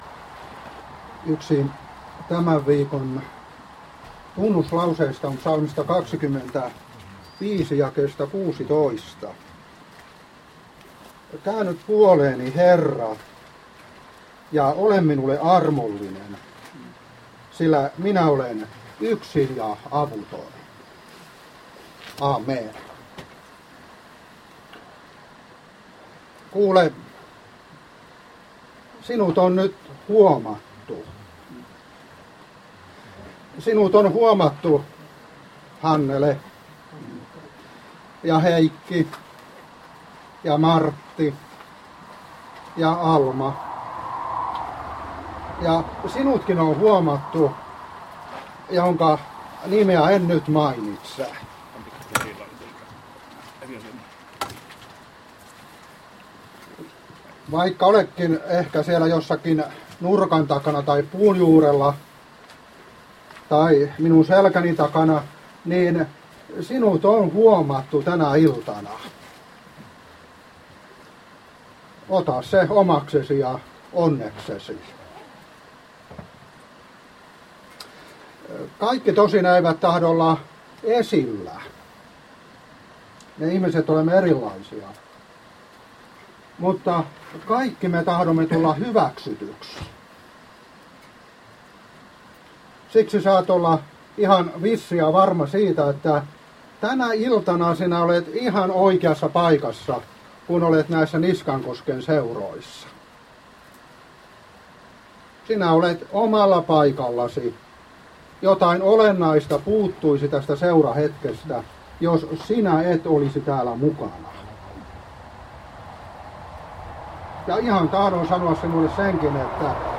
Niskankosken luontoseuroissa Tekstinä Ps. 25: 16